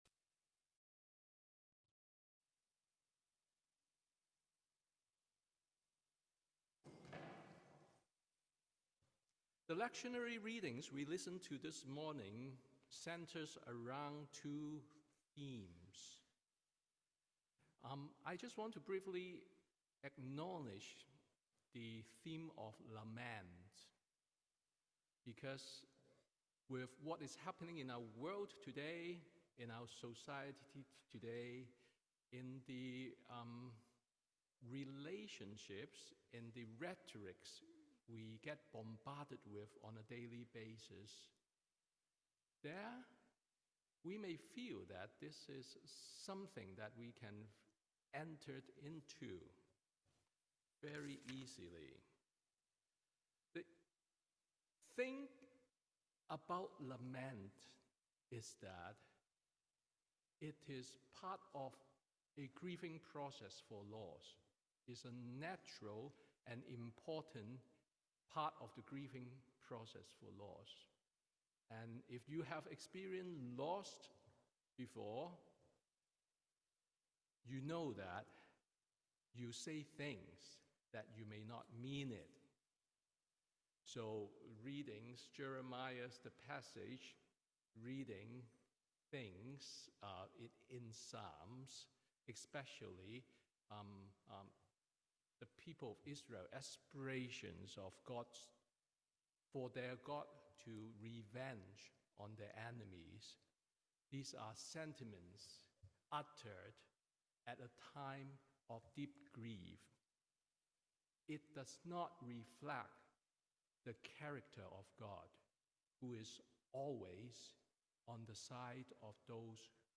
Sermon on the Third Sunday of Creationtide